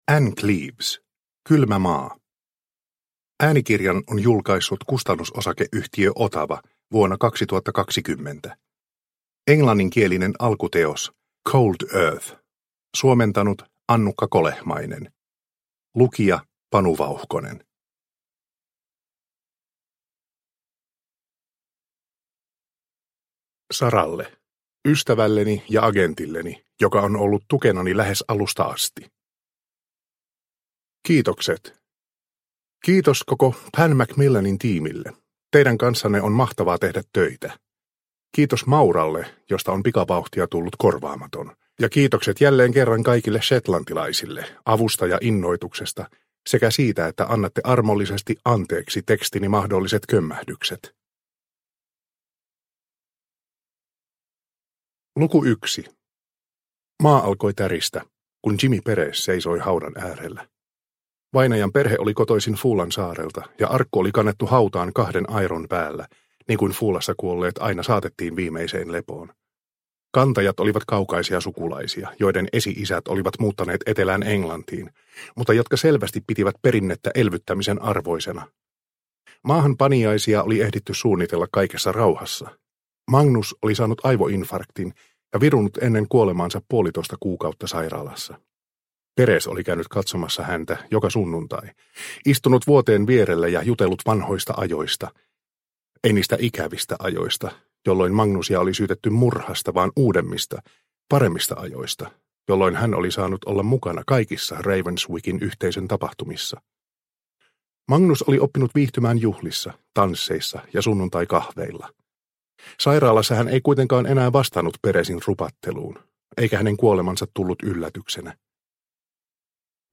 Kylmä maa – Ljudbok – Laddas ner